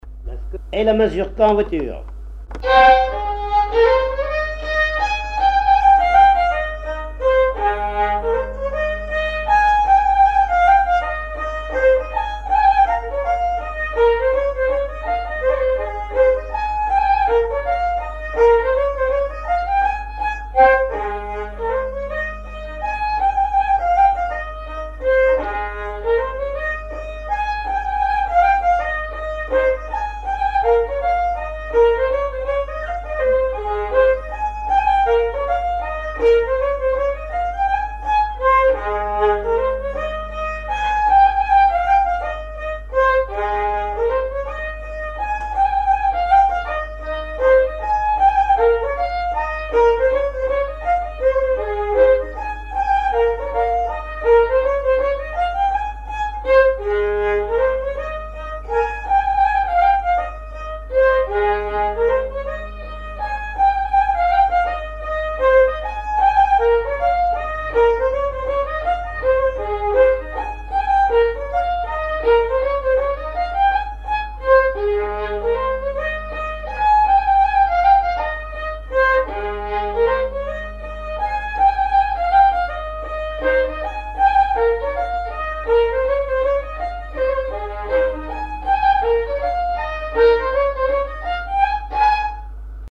danse : mazurka
recherche de répertoire de violon pour le groupe folklorique
Pièce musicale inédite